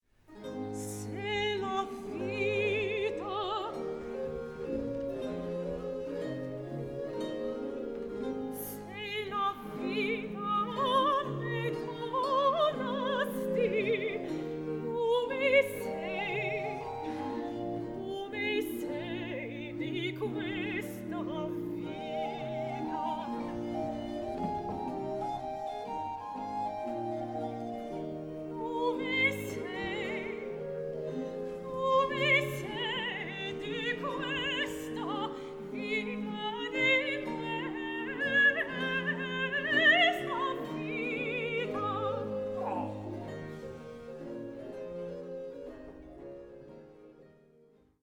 dramma per musica in three acts
7 Aria